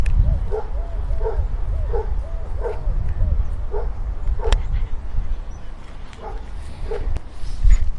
电动割草机
描述：运行一个电动割草机，听起来类似于除草机，在薄薄的草和杂草以及木兰树上的一些大的干叶上。录音冷冷地停止了。
标签： 设备 现场录音 草坪护理 运动 户外 郊区 城市
声道立体声